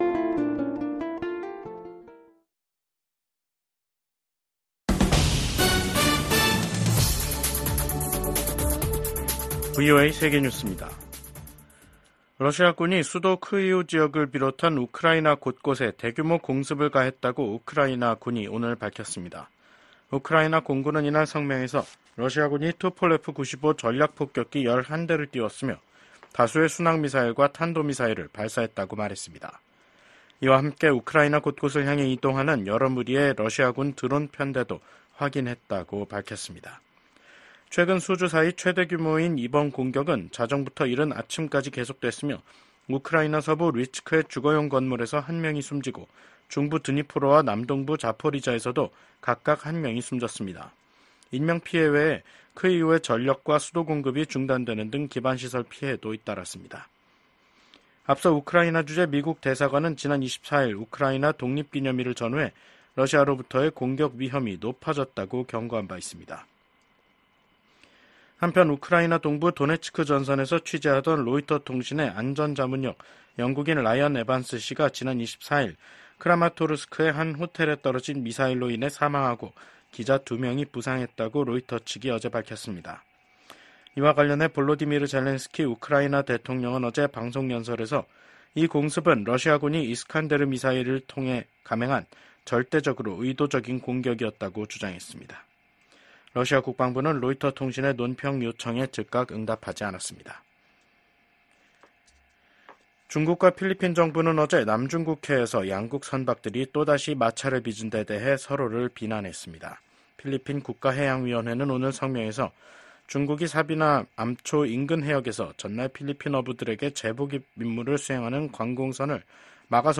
VOA 한국어 간판 뉴스 프로그램 '뉴스 투데이', 2024년 8월 26일 2부 방송입니다. 미국 백악관 국가안보보좌관이 중국을 방문해 북한 문제 등 미중 현안을 논의합니다. 북한이 김정은 국무위원장의 현지 지도 아래 자신들이 만든 자폭형 무인공격기를 처음 공개했습니다.